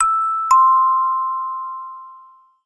Door_Chime.ogg